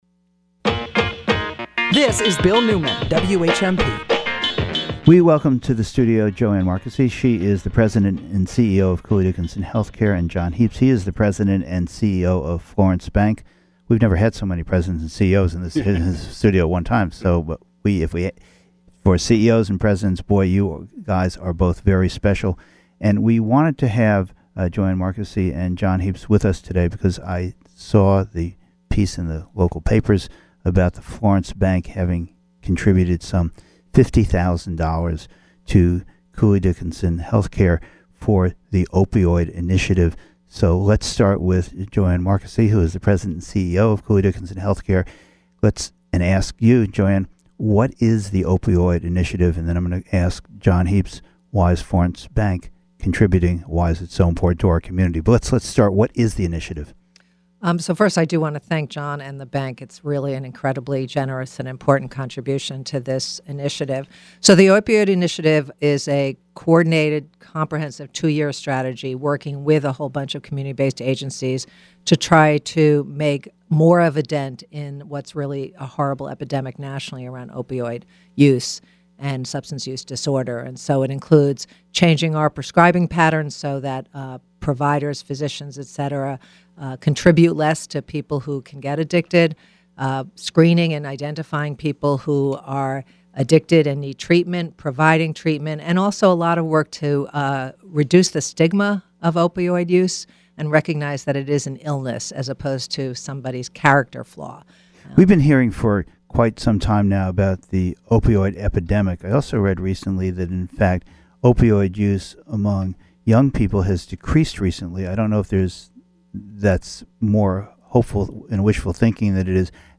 Courtesy of WHMP radio. https